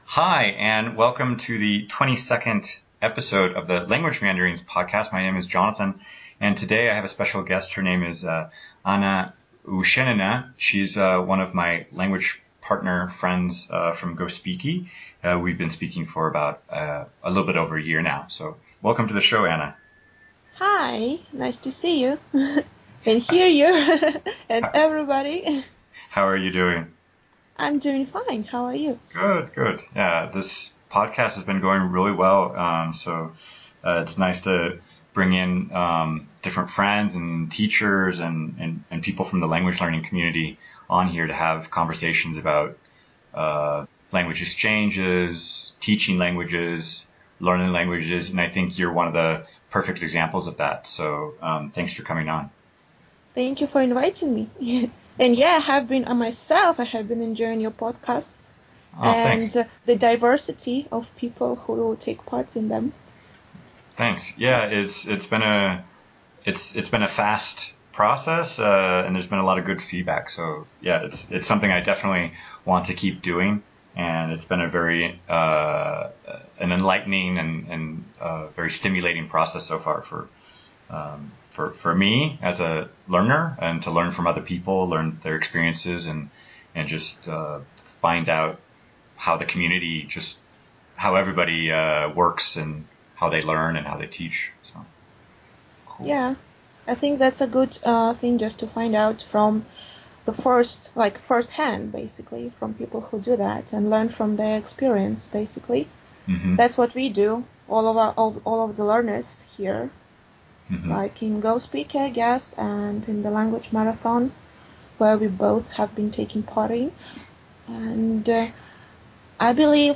Episode 22 - Interview